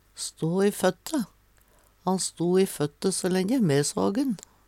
stå i føtte - Numedalsmål (en-US)